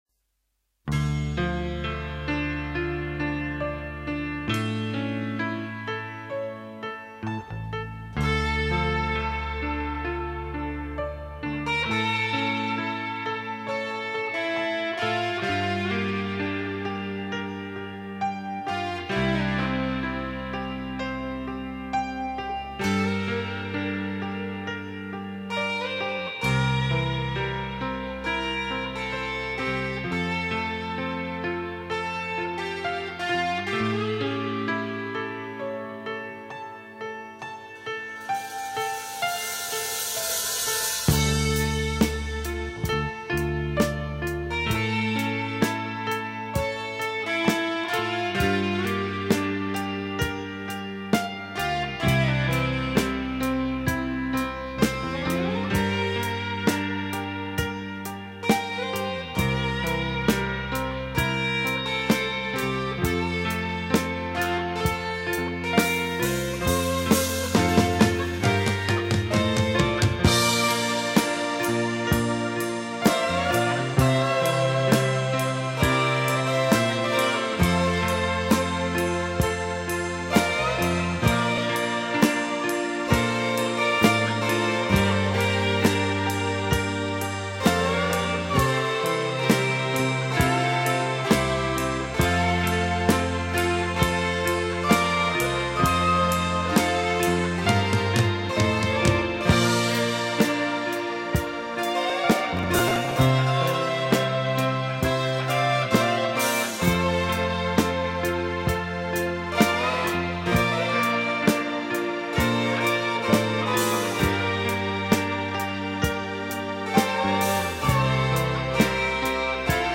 Rock-Music: